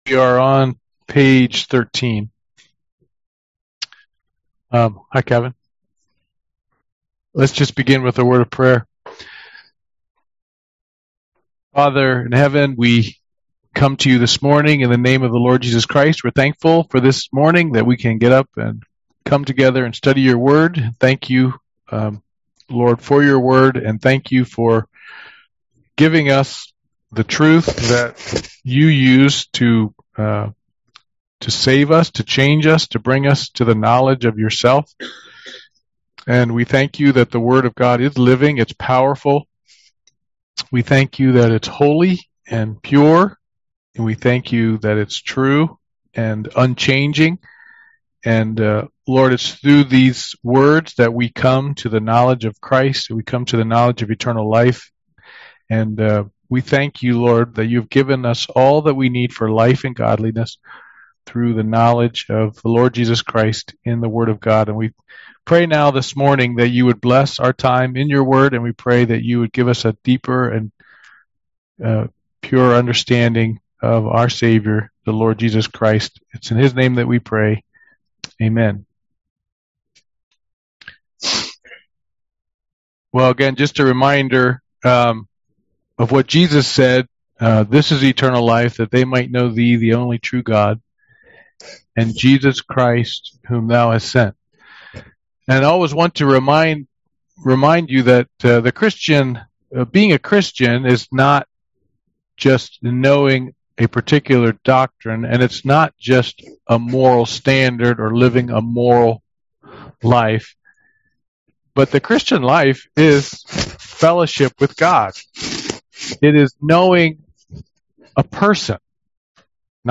UN Service Type: Men's Bible Study « Membership Class